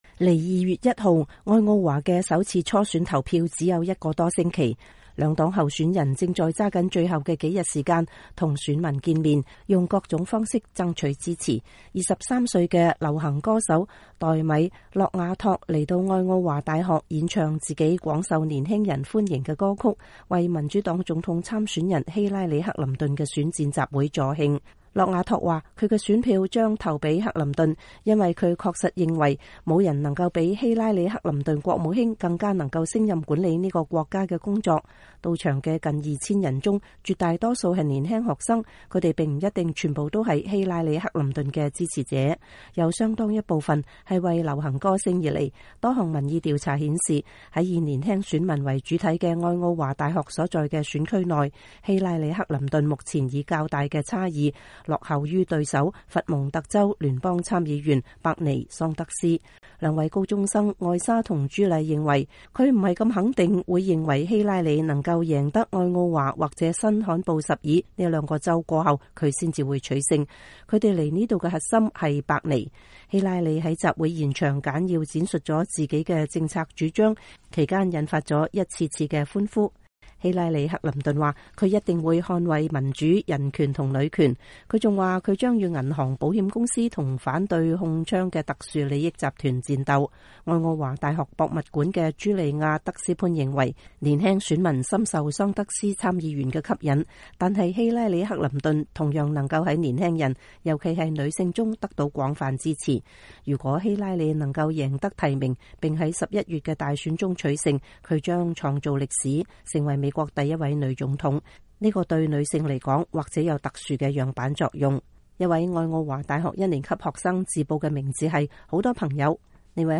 23歲的流行歌手黛米·洛瓦託來到愛奧華大學演唱自己廣受年輕人歡迎的歌曲，為民主黨總統參選人希拉里·克林頓的選戰集會助興。
希拉里在集會現場簡要闡述了自己的政策主張，其間引發了一次次歡呼。